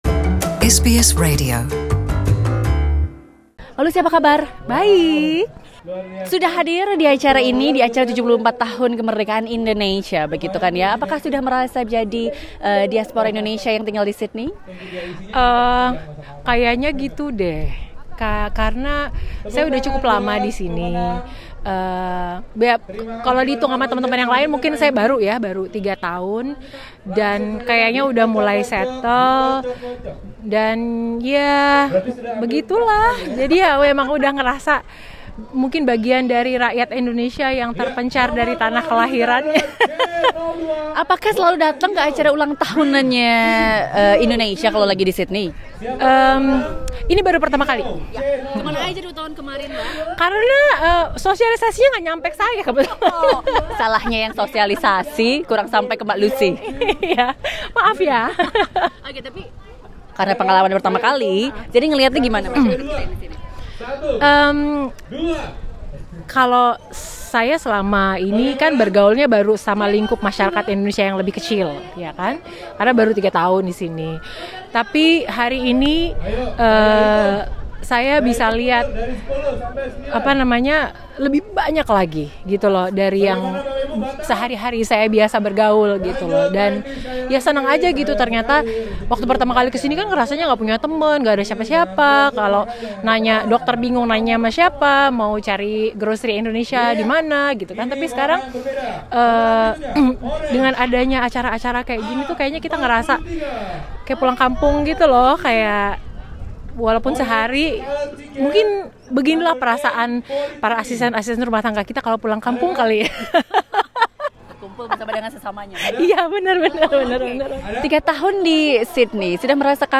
SBS Indonesian berbincang dengan Lusy terkait hal ini, dan juga apa yang kini menjadi prioritasnya.